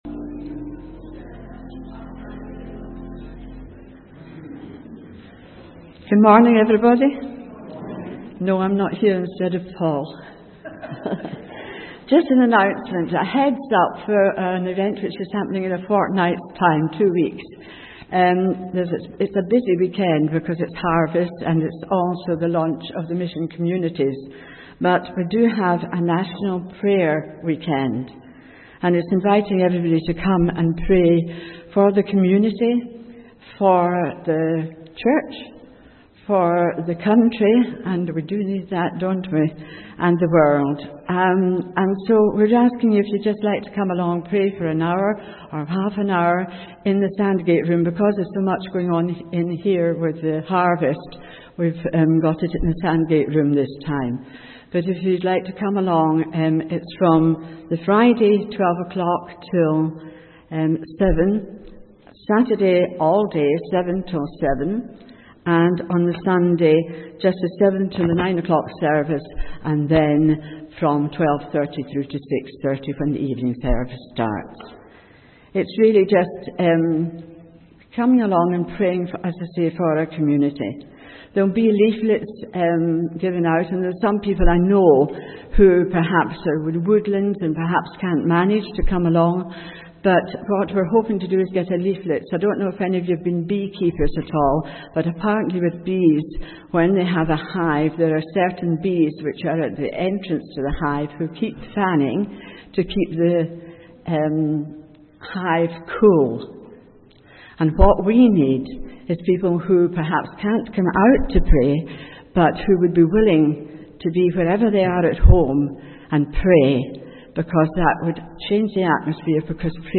A message from the series
From Service: "9.00am Service"